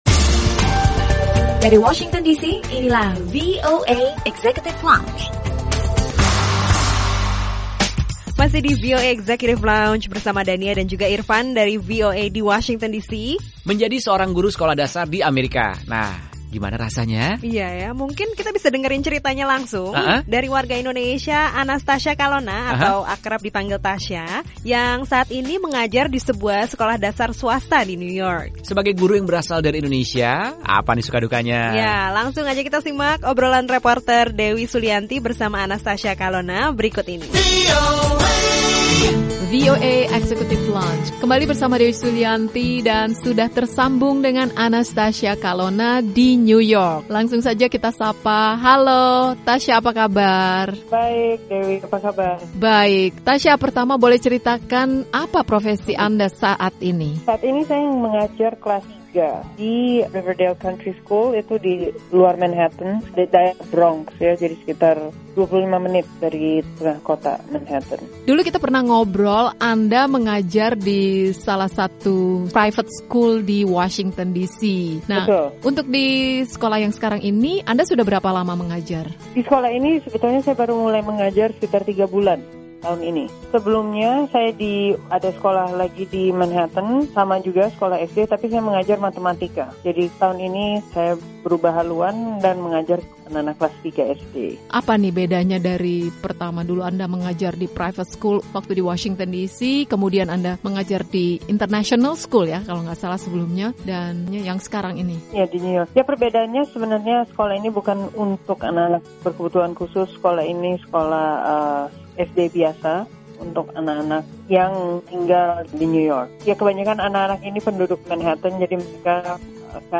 Bagaimana suka dukanya? Dan apa saja tantangannya? Simak obrolannya di VOA Executive Lounge kali ini.